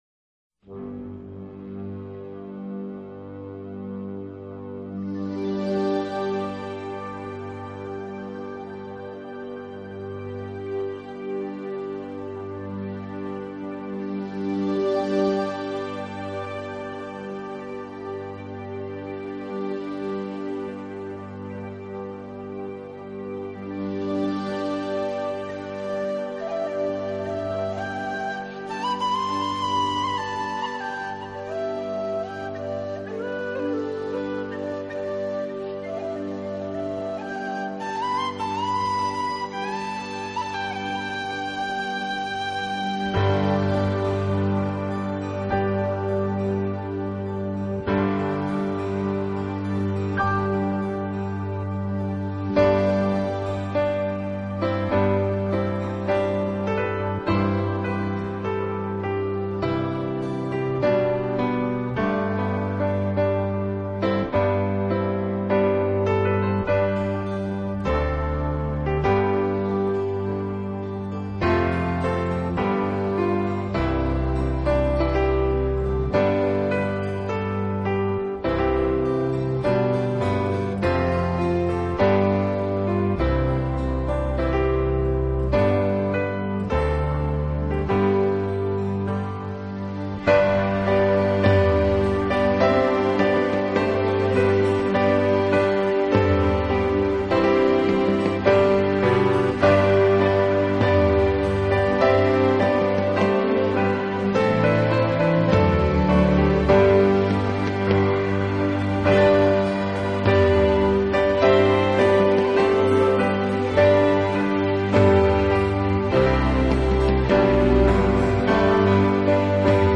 钢琴专辑
音乐类型：New age/Celtic/fusion/contemporary